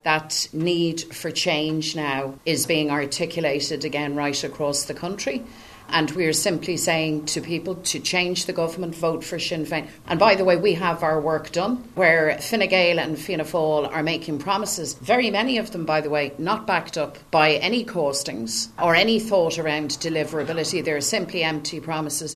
Sinn Féin leader Mary Lou McDonald says the poll reflects what they’re hearing on the doors.